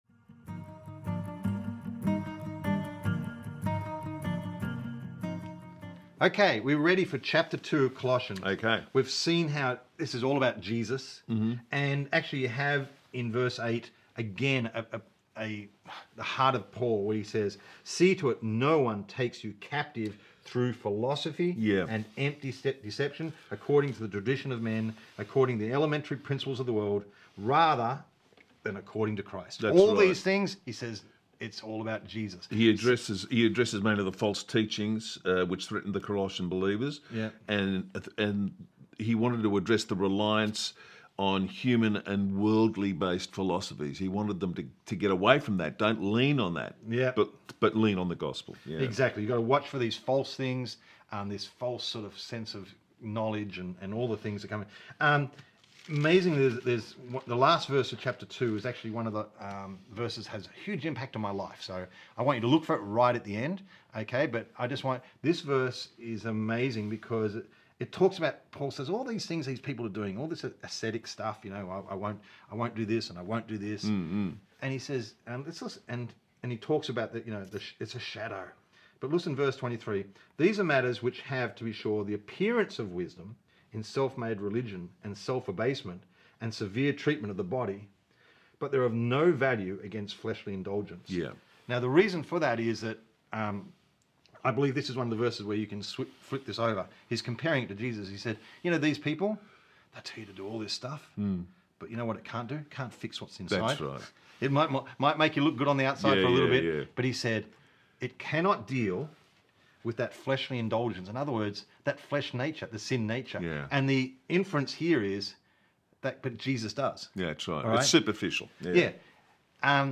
Bible readings from the Twentieth Century New Testament with Bible Teacher